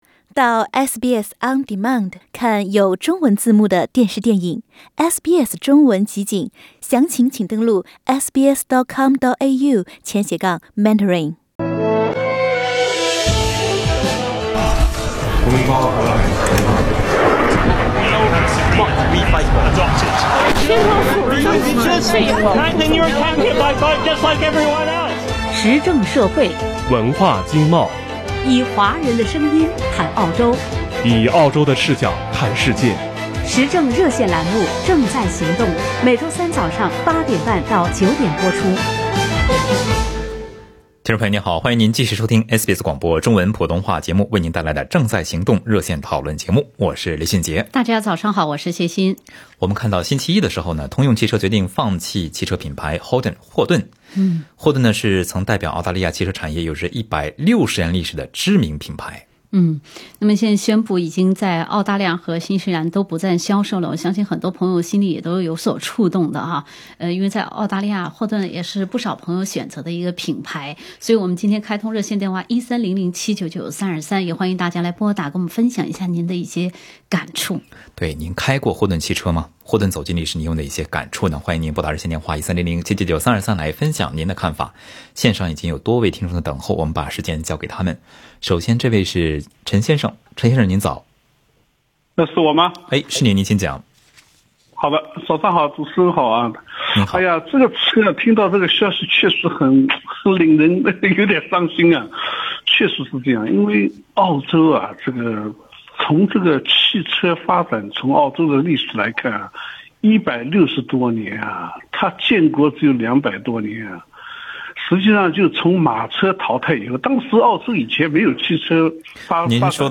在本期《正在行動》熱線討論節目中，有聽眾也分析了霍頓品牌走向衰落的原因。